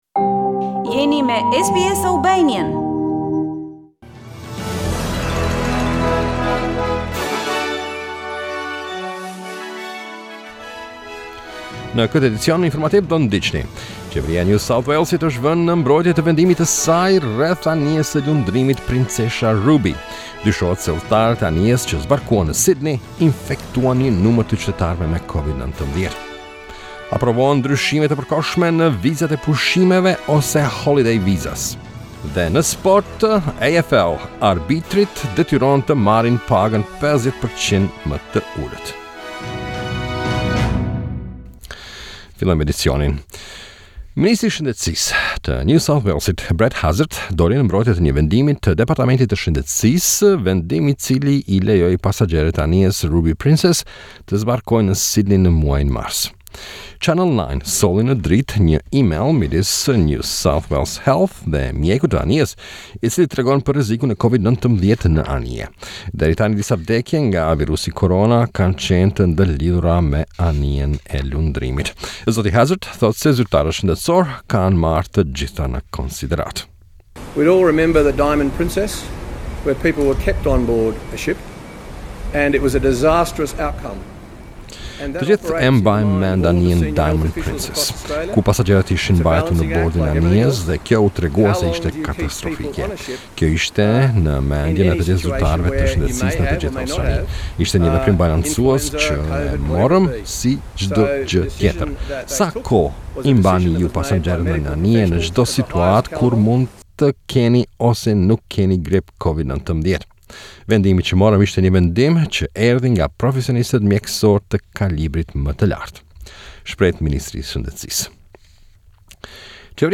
SBS News Bulletin 04 April 2020